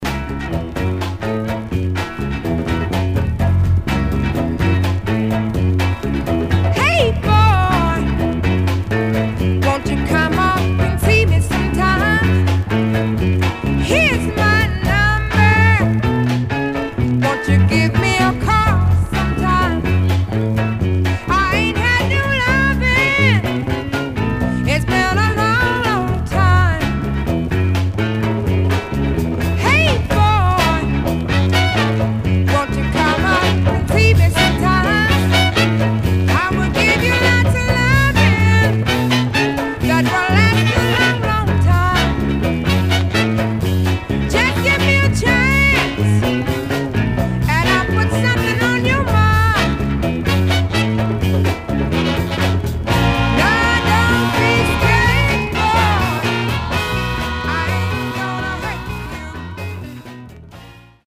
Mono
Soul